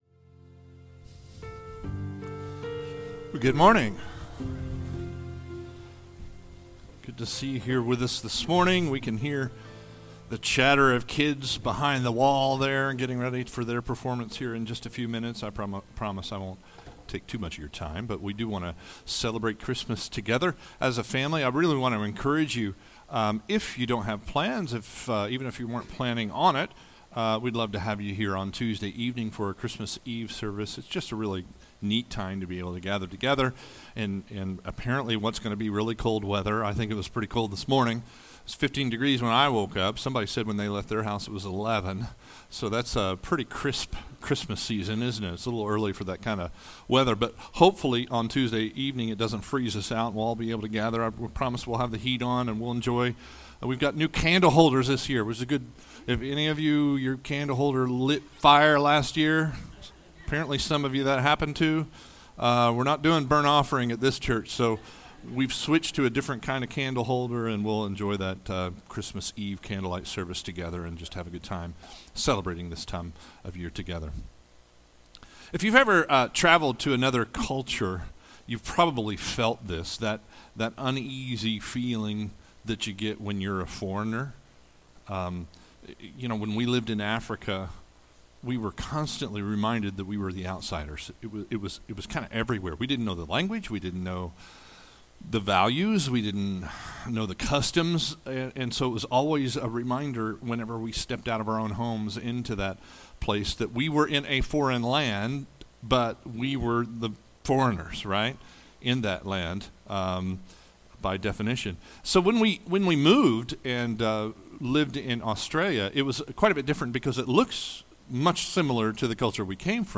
Sermons | Watermarke Church